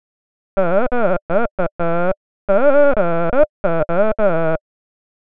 F28G1FT2A_fmHum.wav